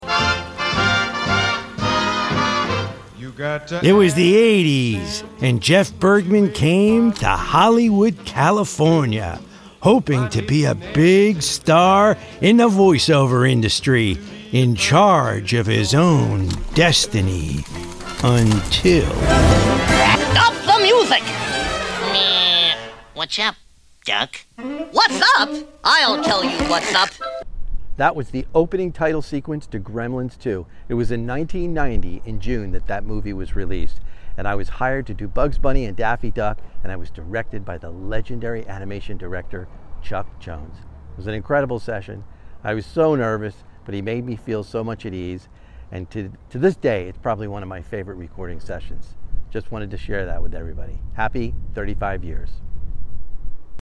This is the opening title sequence that played before the film.